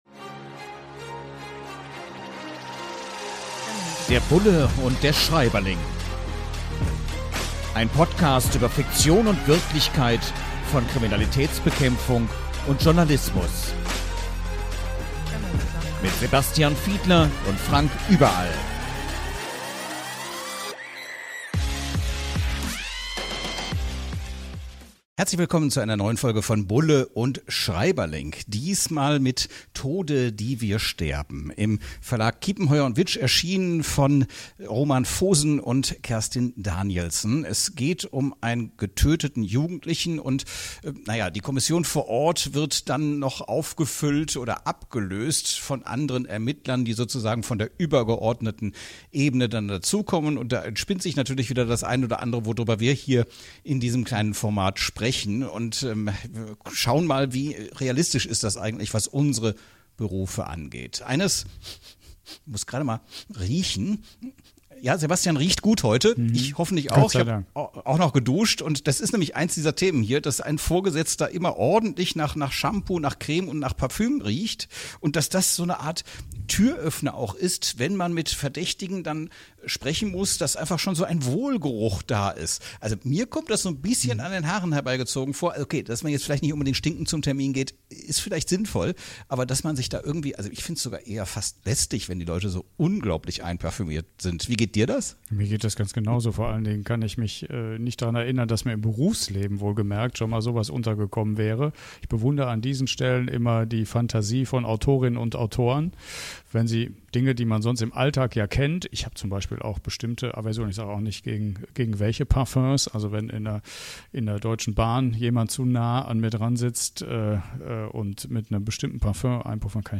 In launiger Atmosphäre tauschen sich die Beiden anhand von Aspekten aus, die in dem Buch beschrieben werden.